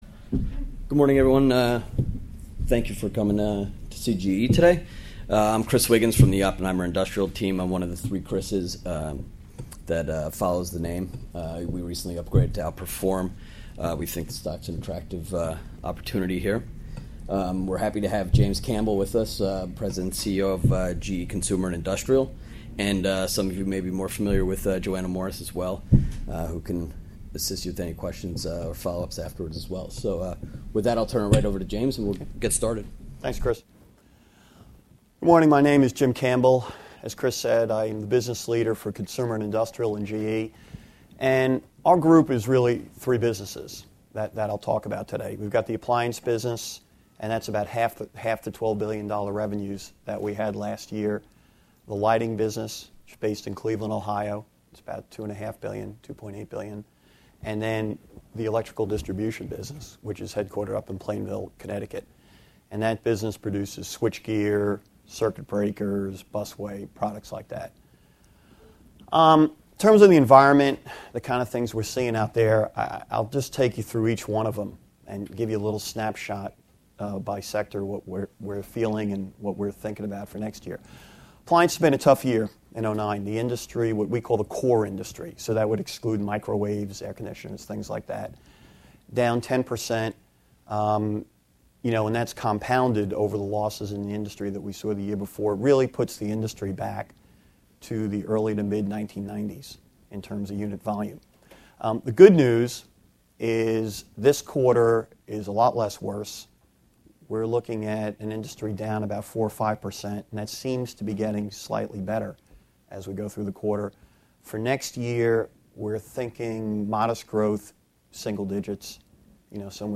Oppenheimer 4th Annual Industrials Conference | General Electric